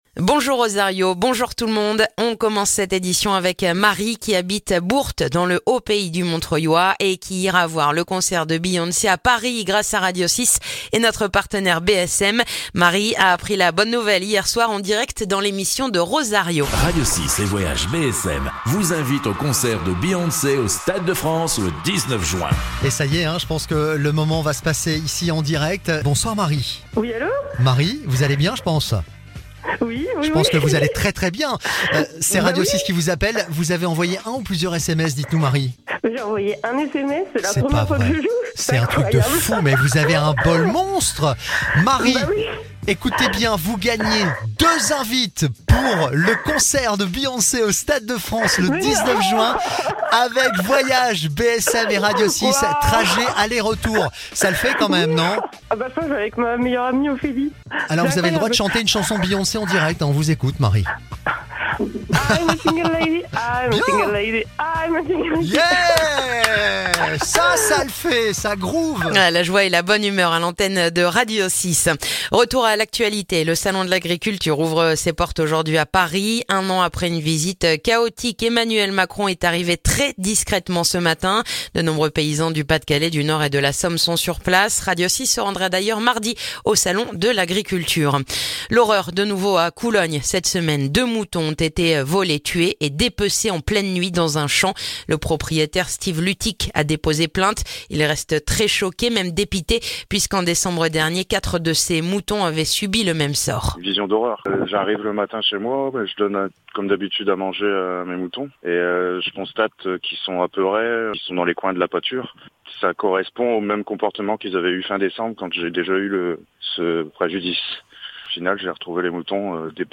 Le journal du samedi 22 février